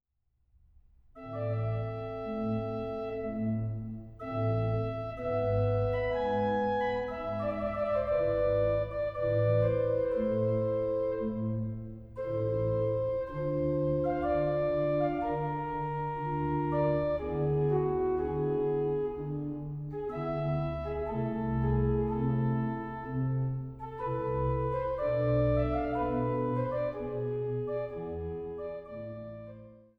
Norddeutscher Orgelbarock